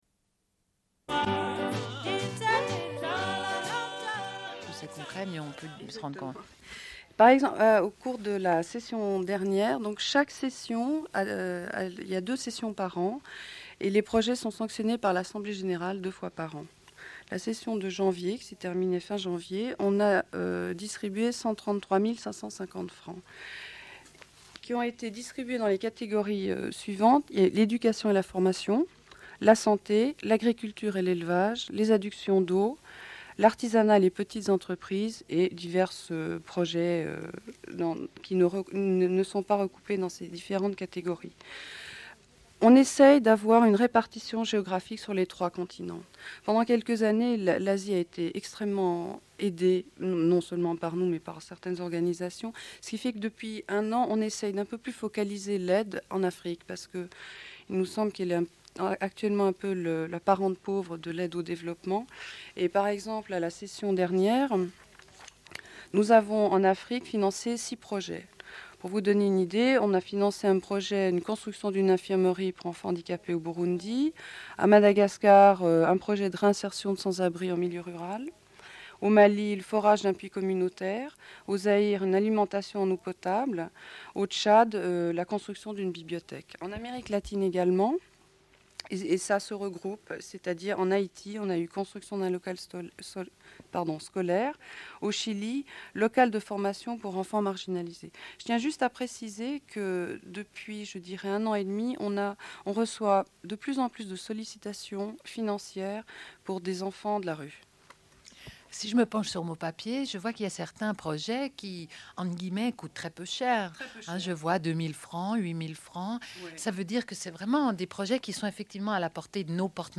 Une cassette audio, face B29:11
Fin de la cassette : Bulletin d'information de Radio Pleine Lune du jour.